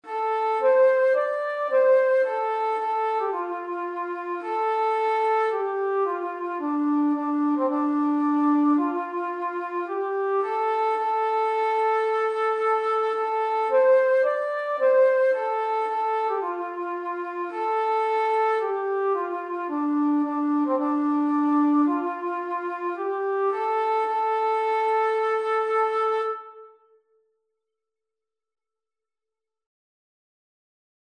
Voor 3 stemmen